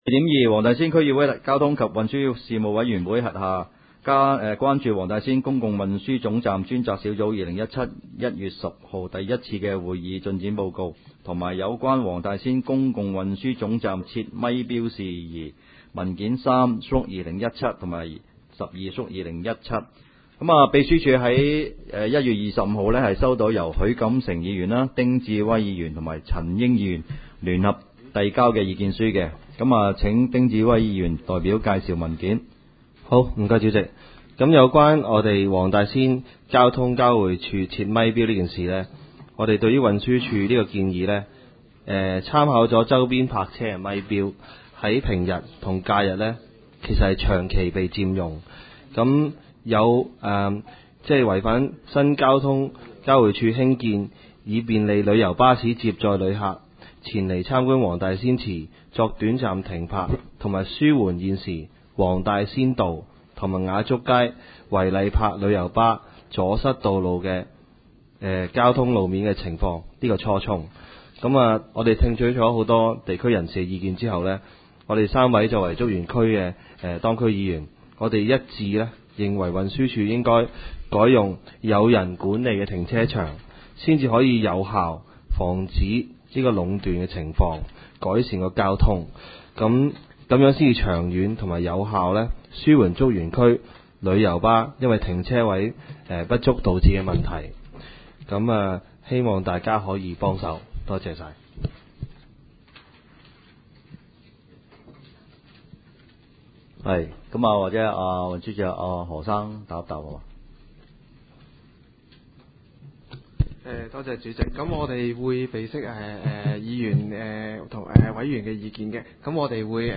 委員會會議的錄音記錄